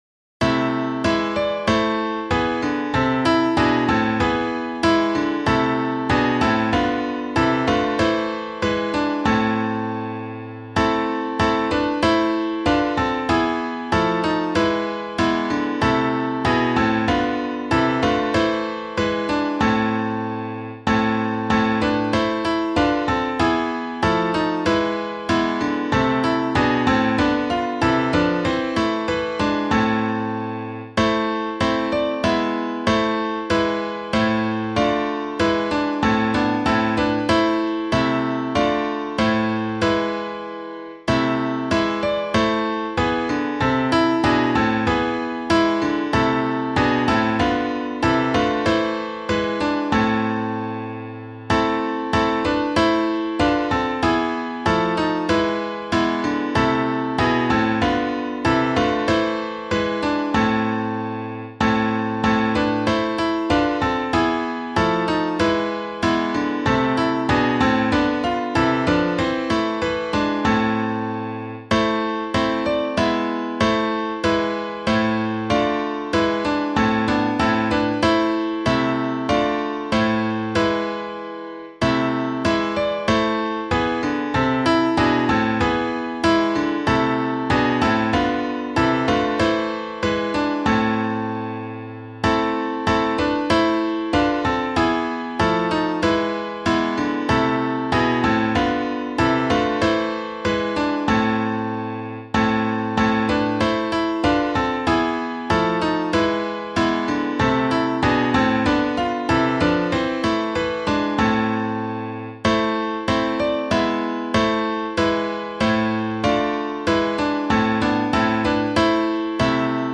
Practice singing the hymns for this Sunday’s worship services using the sheet music and audio accompaniment below.